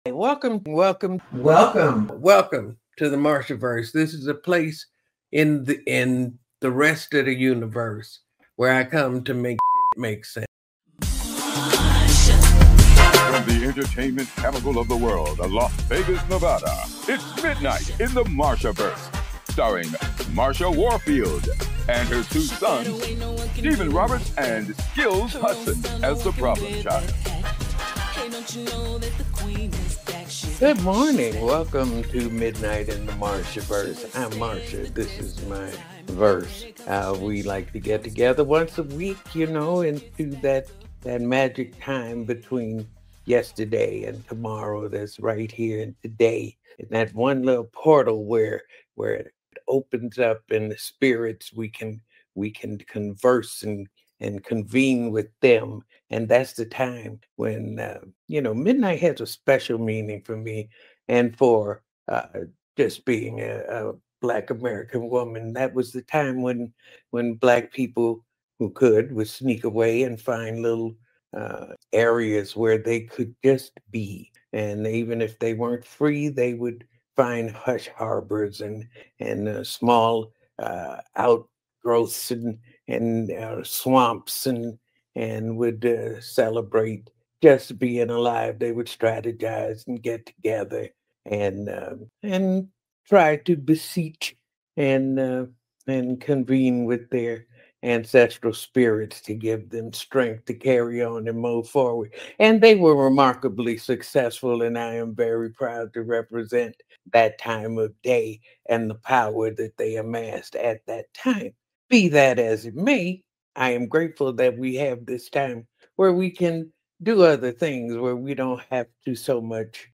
This week on Midnight in the Marshaverse, the legendary Marsha Warfield welcomes comedian, actor, and screenwriter Joe Torry for a candid conversation about his comedy roots, his admiration for Sammy Davis Jr., and the trials of surviving Hollywood’s pitfalls.